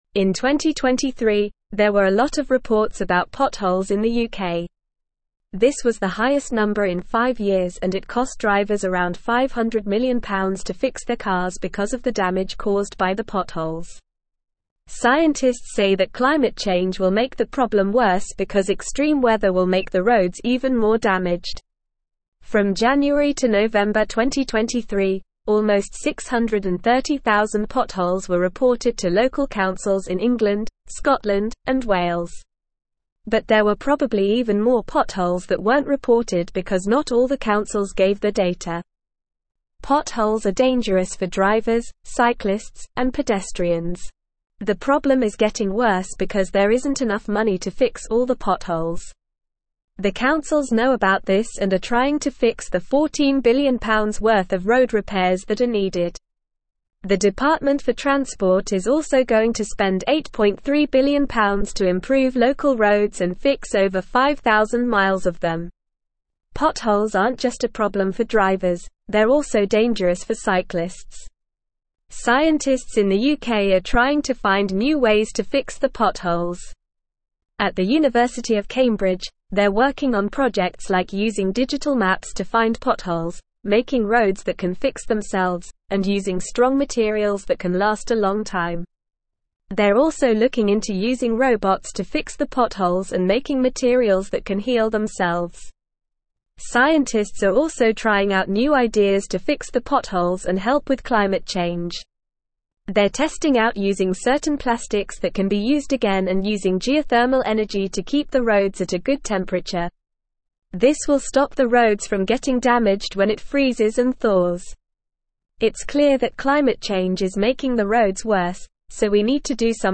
English-Newsroom-Upper-Intermediate-NORMAL-Reading-UK-Pothole-Problem-Climate-Change-and-Innovative-Solutions.mp3